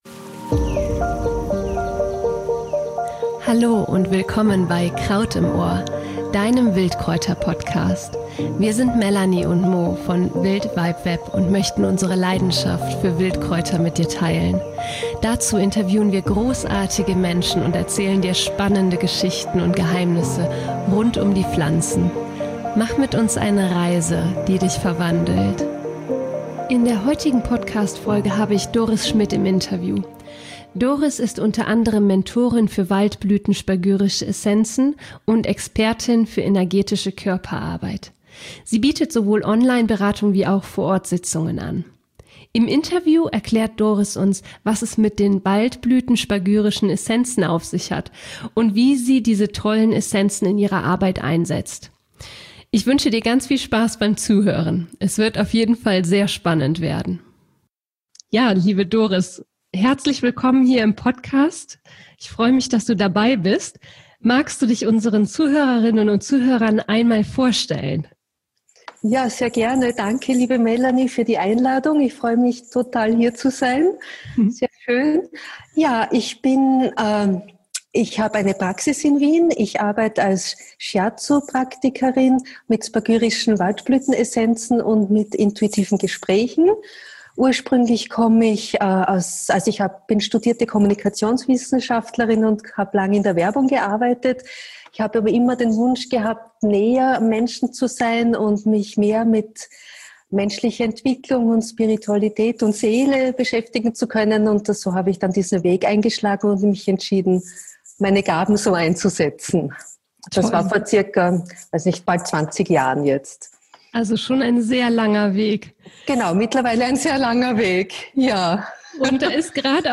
Kraut-Interview: Das Geheimnis spagyrischer Waldblüten-Essenzen.